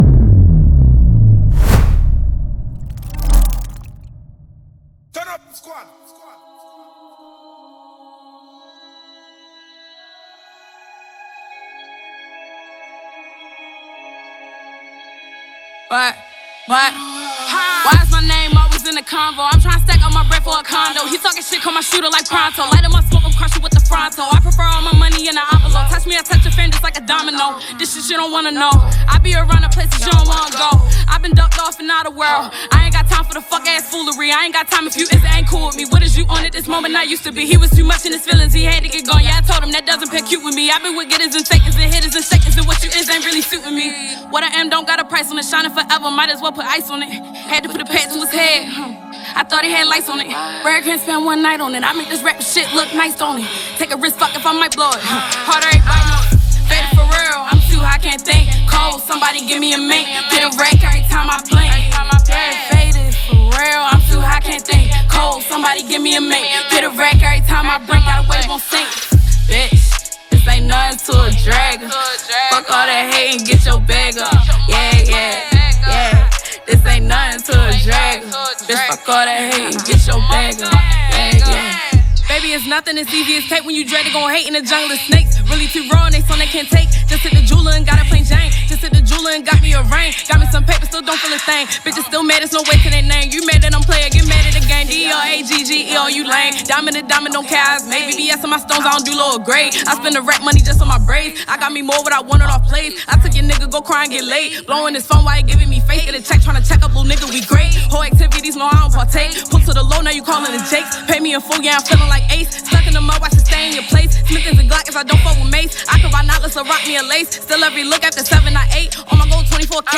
With infectious rhythms and captivating verses